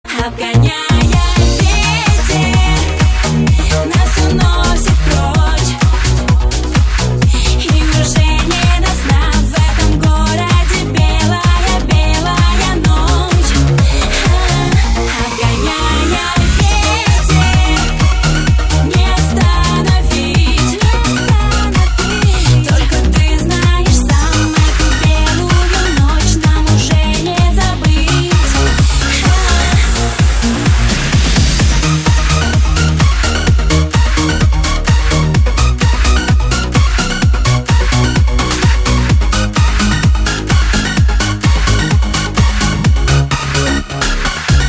remix
Eurodance